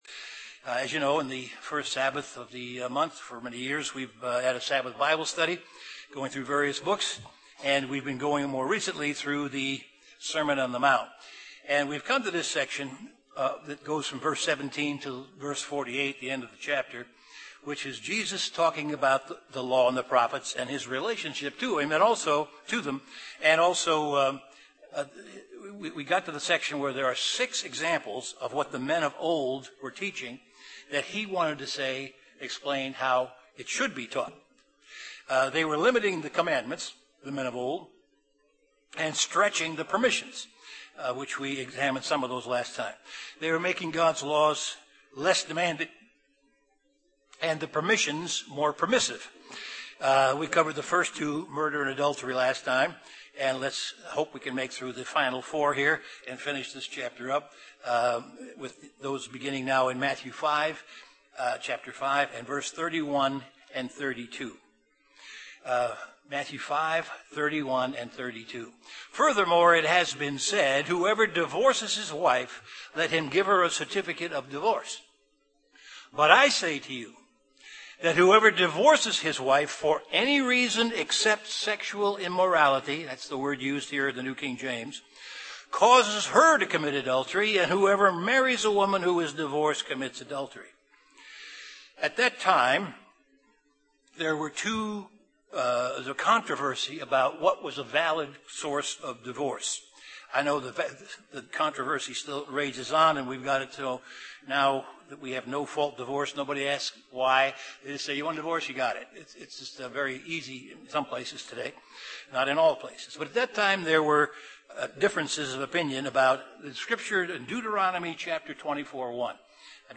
Continuation of an in-depth Bible Study on the Sermon on the Mount.
Given in Chicago, IL